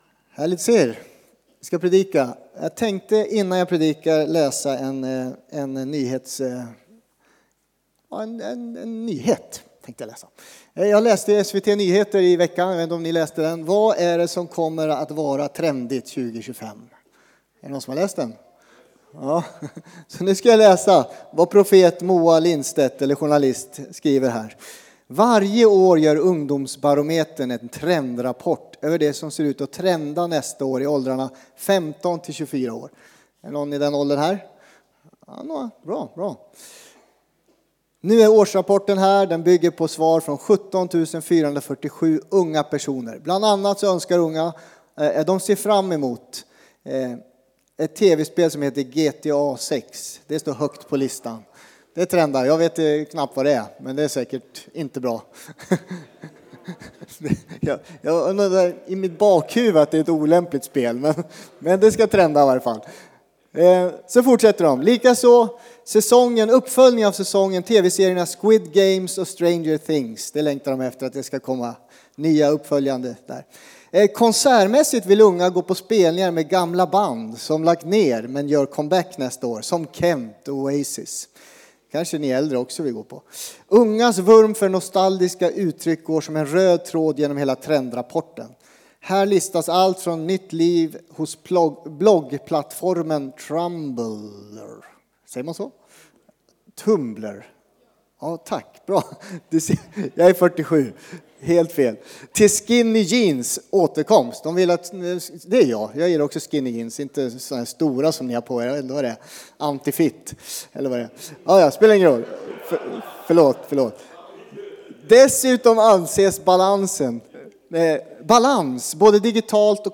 Furuhöjdskyrkan, Alunda Predikan